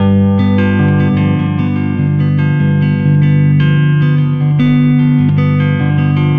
Weathered Guitar 01.wav